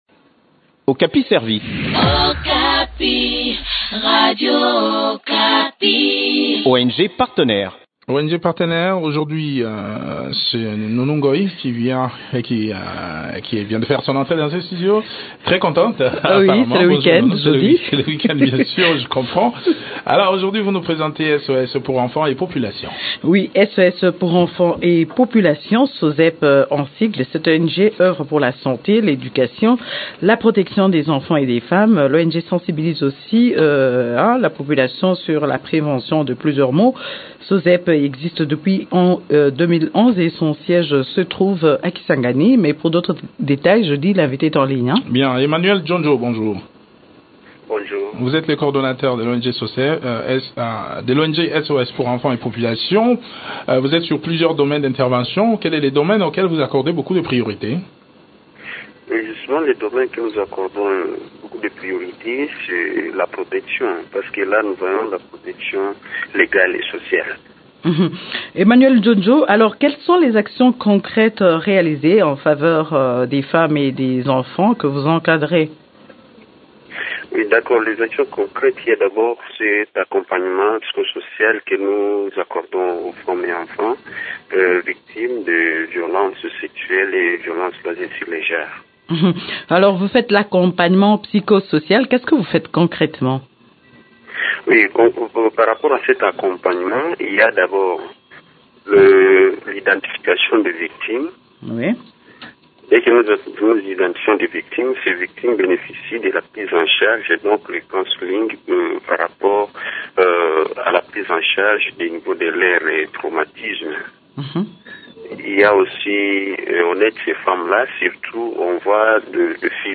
Okapi service, Émissions / Leadership, Gouvernance, féminin